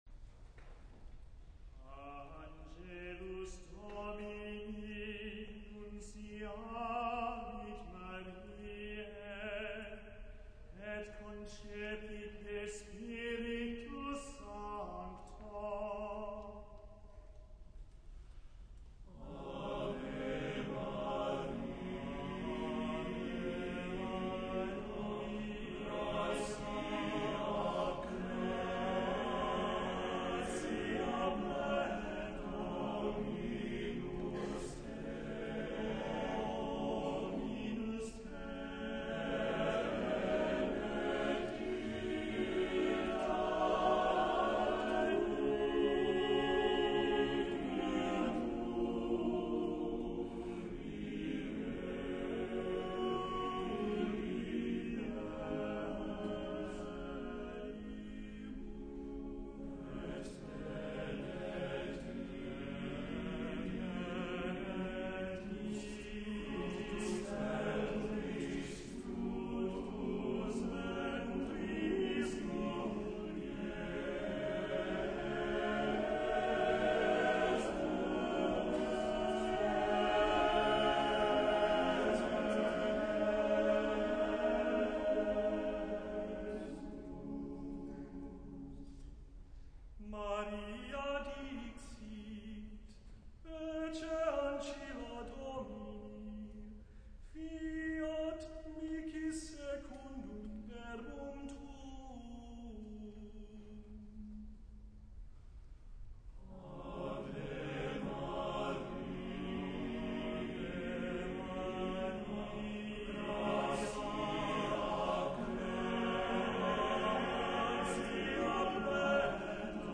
Emory Community Choir Concert, December 2000
Well, the recordings of the Lessons and Carols performance are in, and the Ave Maria was easily the best song of the show.